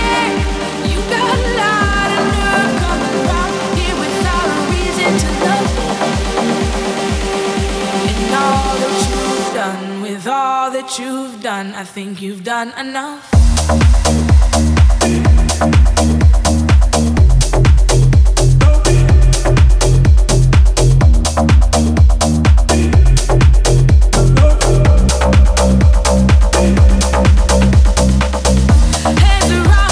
• Dance